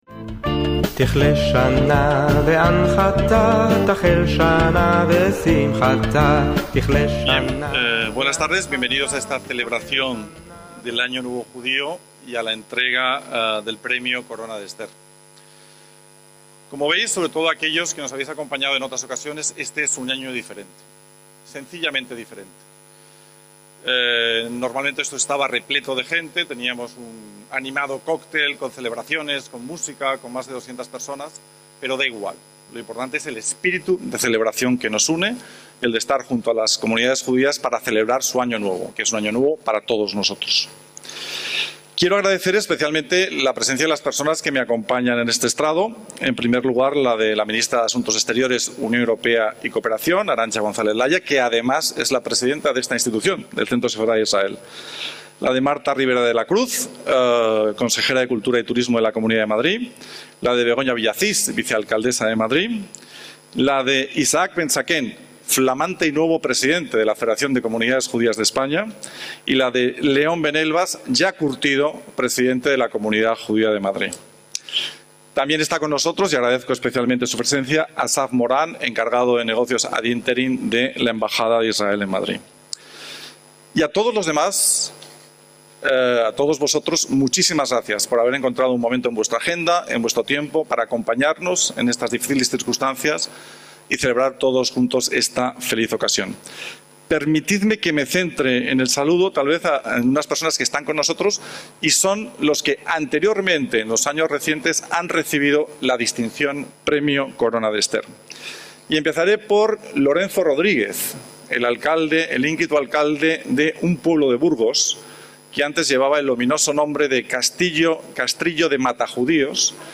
DE ACTUALIDAD - El 16 de septiembre de 2020 tuvo lugar en la sede del Centro Sefarad Israel de Madrid la tradicional celebración del Año Nuevo Judío (Rosh Hashaná), este año marcado por las restricciones de aforo impuestas por la pandemia.